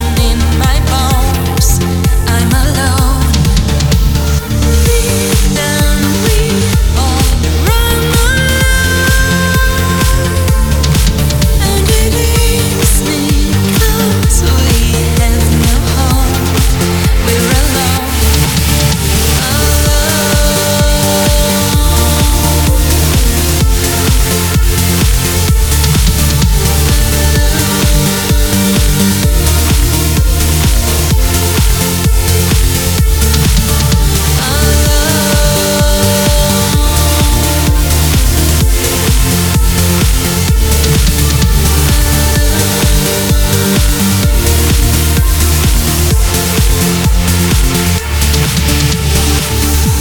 громкие
Electronic
vocal trance